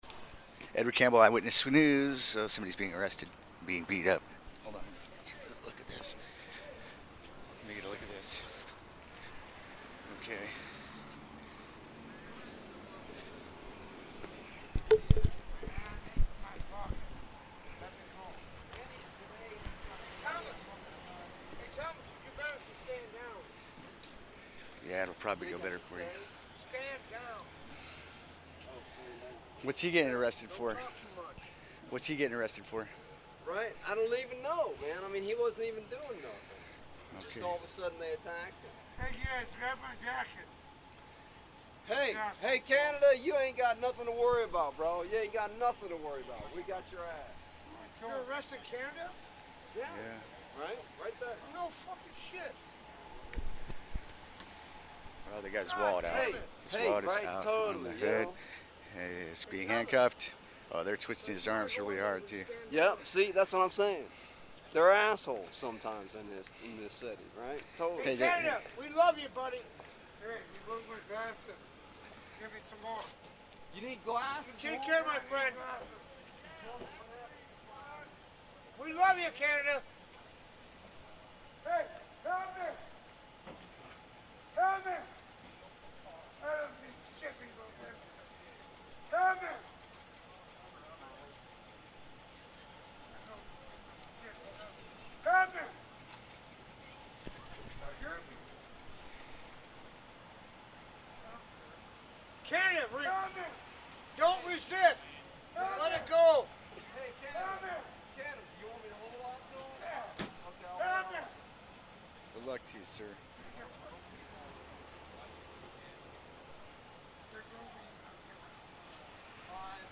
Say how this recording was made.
Here is the unedited audio.